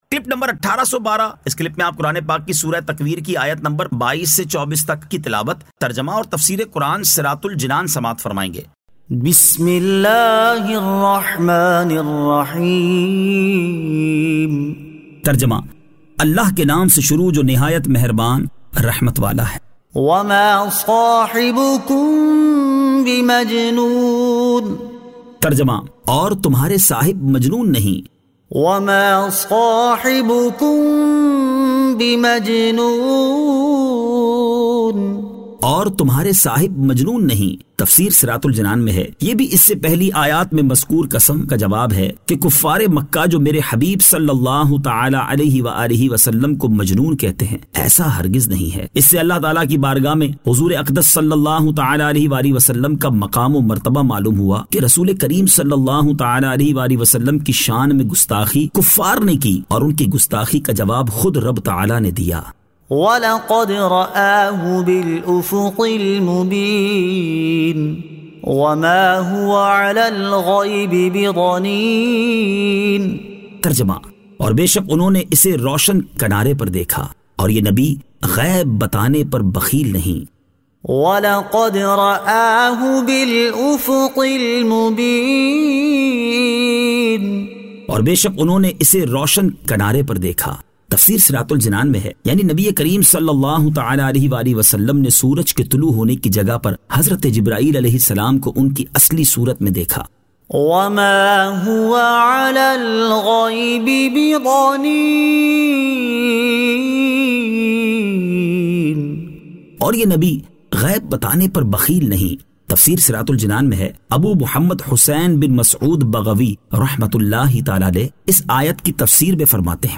Surah At-Takwir 22 To 24 Tilawat , Tarjama , Tafseer